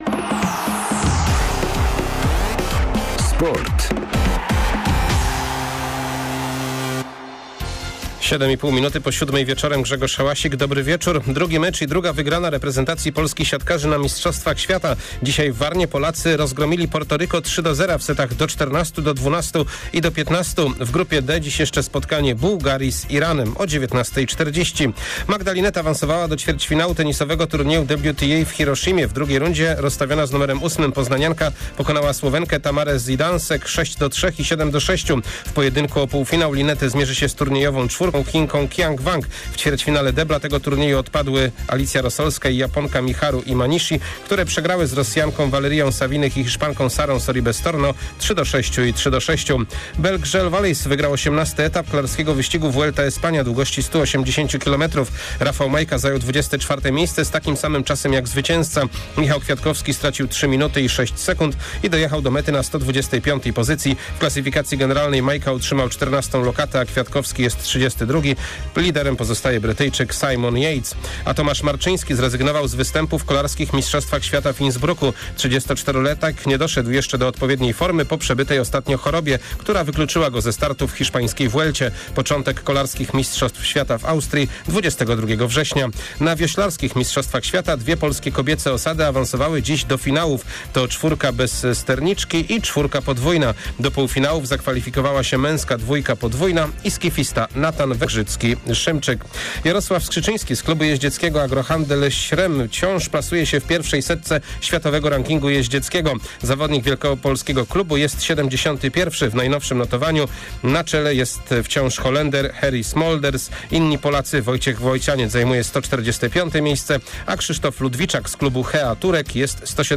13.09. SERWIS SPORTOWY GODZ. 19:05